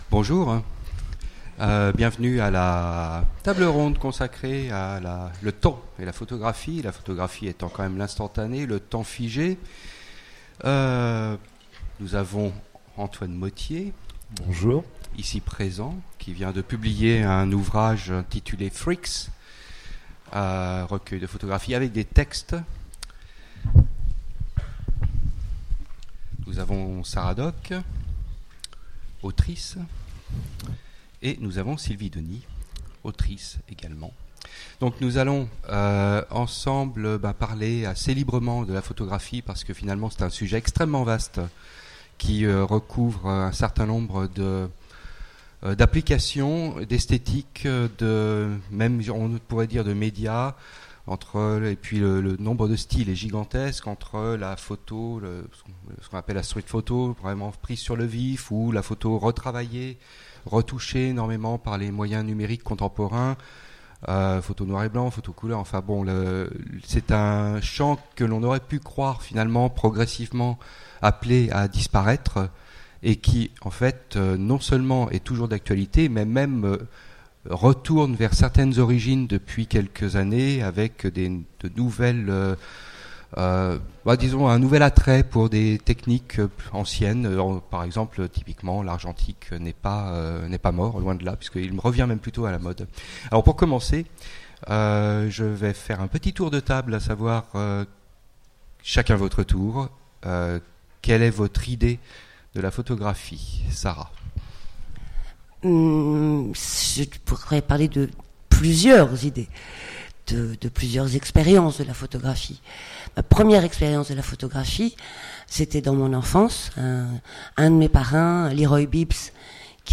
Utopiales 2017 : Conférence Photographie, temps capturé